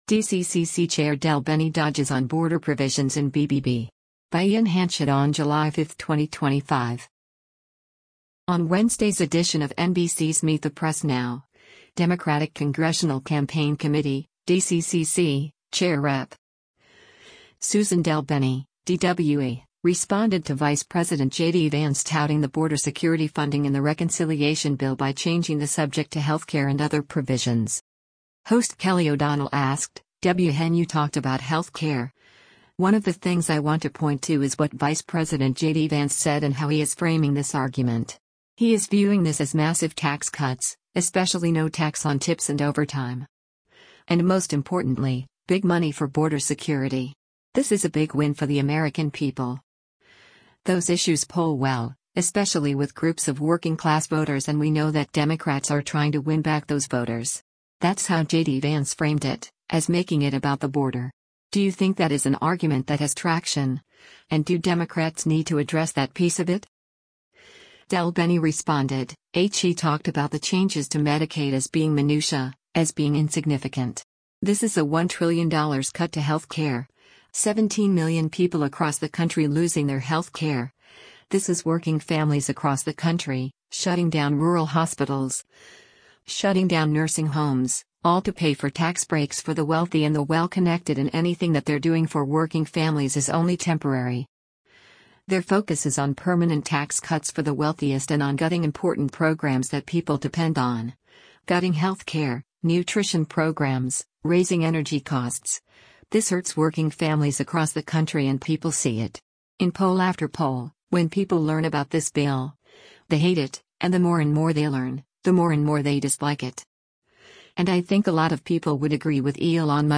On Wednesday’s edition of NBC’s “Meet the Press Now,” Democratic Congressional Campaign Committee (DCCC) Chair Rep. Suzan DelBene (D-WA) responded to Vice President JD Vance touting the border security funding in the reconciliation bill by changing the subject to health care and other provisions.